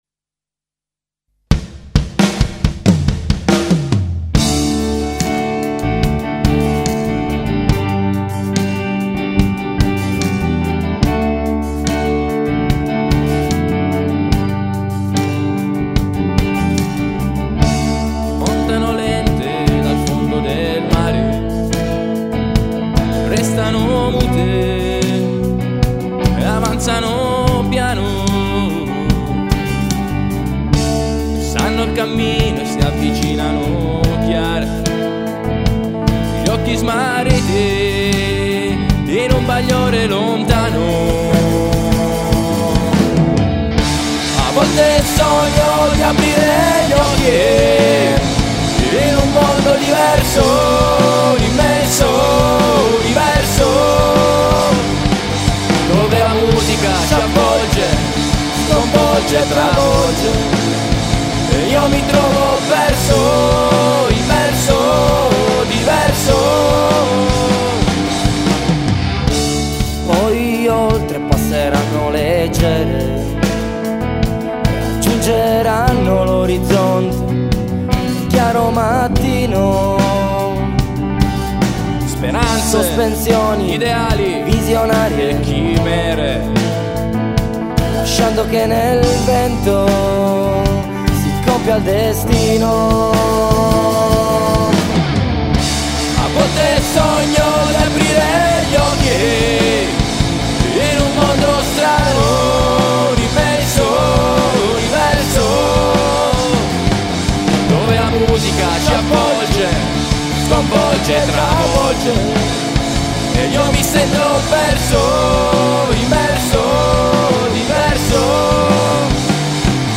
Genere: Punk Rock
Batteria
Chitarra
Basso